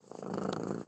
CatPurr.wav